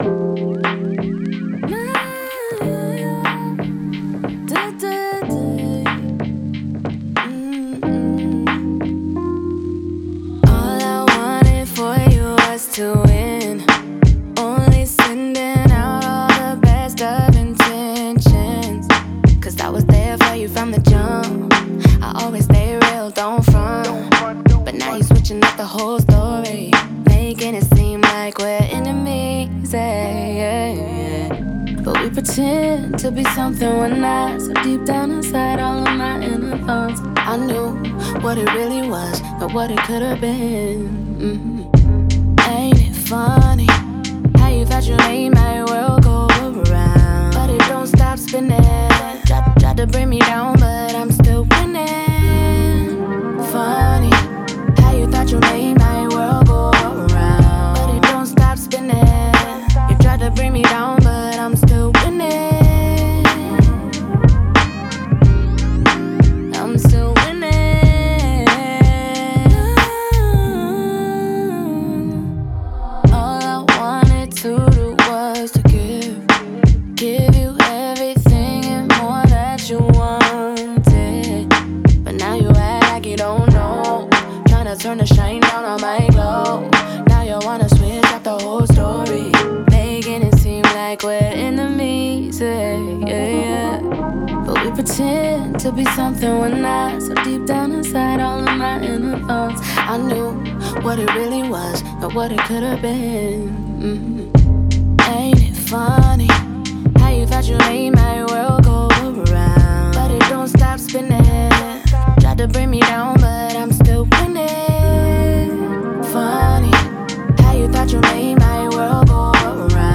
R&B, Hip Hop
F min